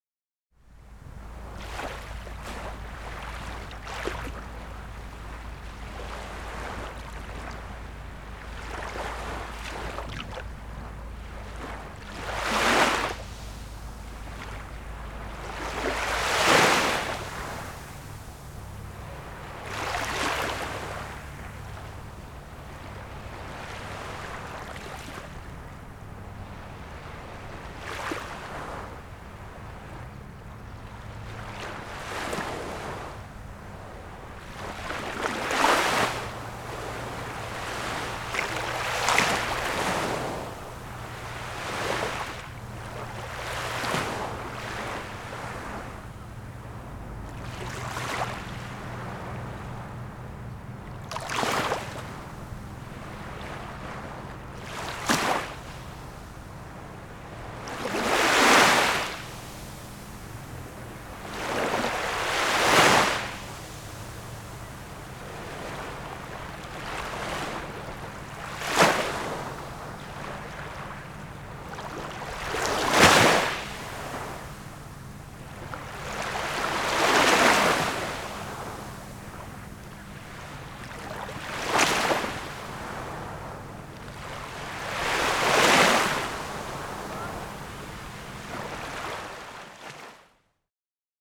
Download Calm Ocean Wave sound effect for free.
Calm Ocean Wave